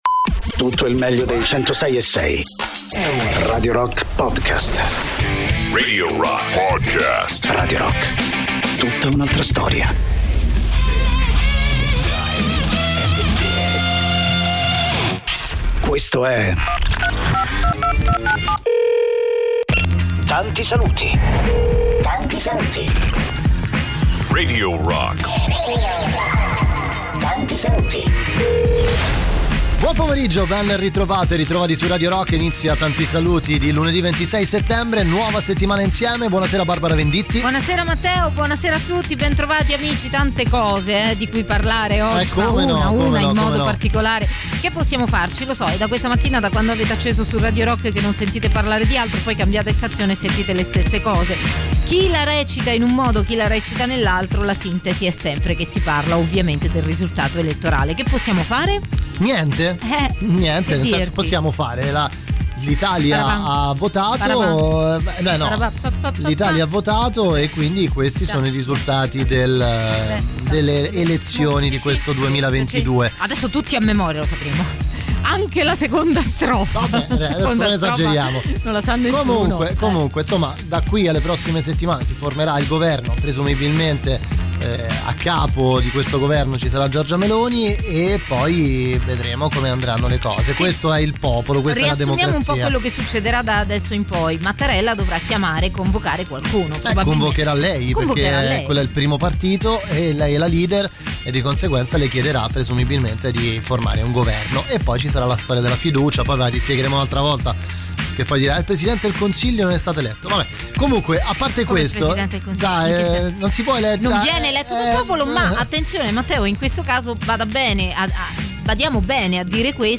con “Tanti Saluti” sui 106.6 di Radio Rock. Podcast del 26 settembre 2022.